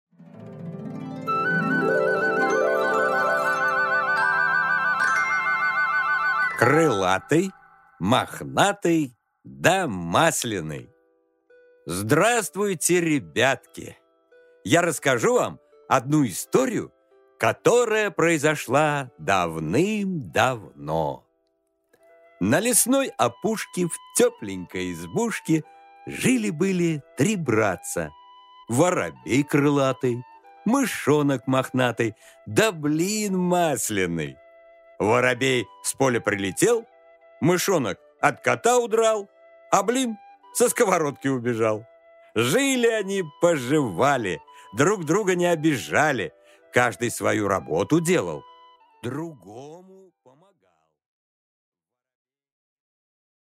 Аудиокнига Крылатый, мохнатый да масленый | Библиотека аудиокниг